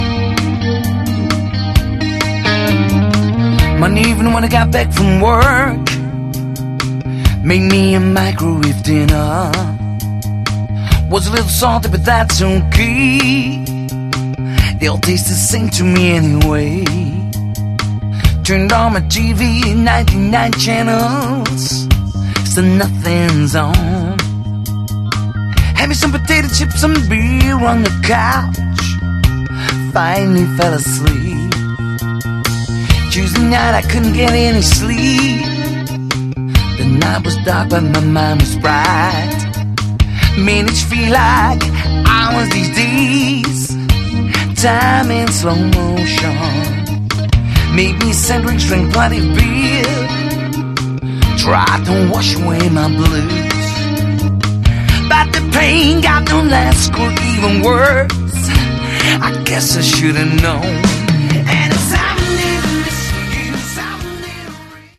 Category: AOR
lead vocals, guitar
drums